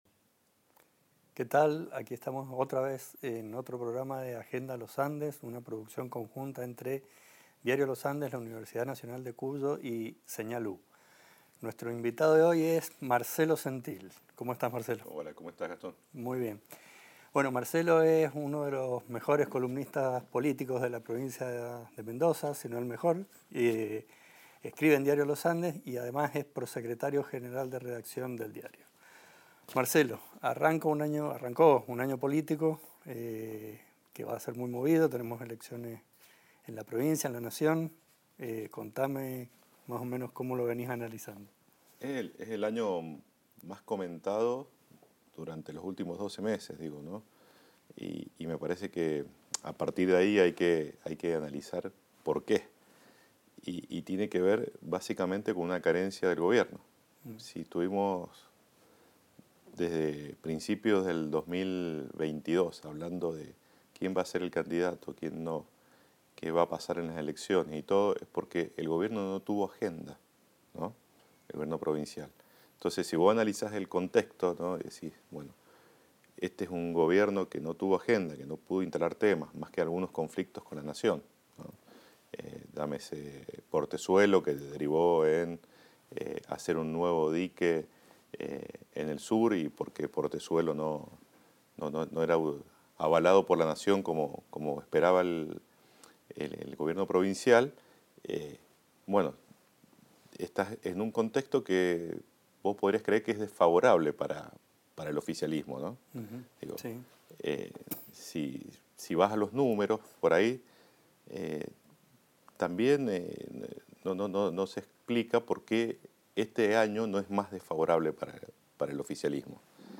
Agenda Los Andes es un programa de Diario Los Andes y la UNCuyo sobre temas de interés general. El ciclo reúne a los principales referentes locales de diversos ámbitos en entrevistas íntimas.